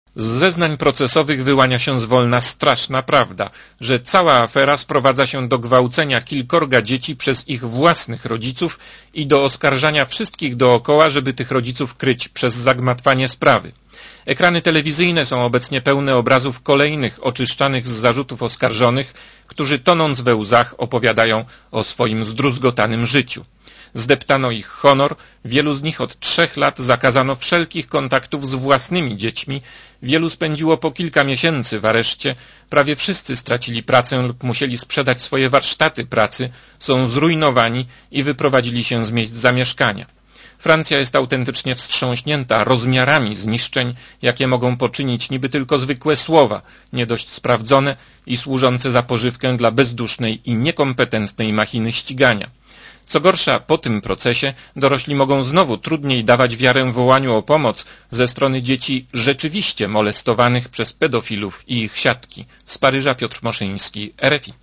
Posłuchaj relacji paryskiego korespondenta Radia ZET (258 KB)